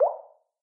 waterdrop-high.mp3